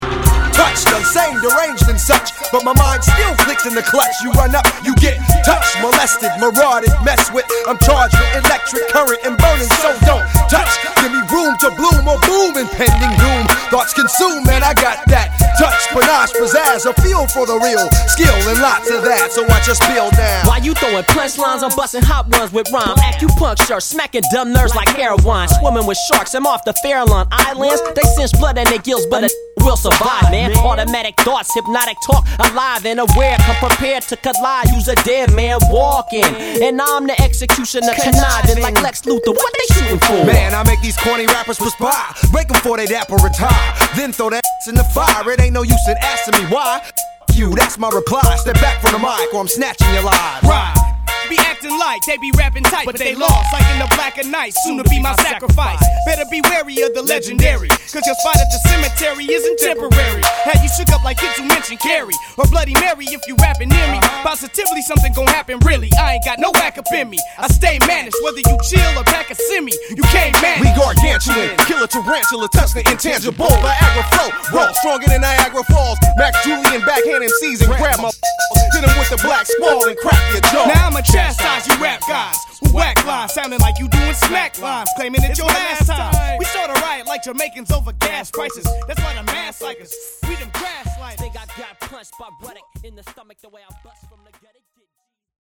lends scratching talents throughout the record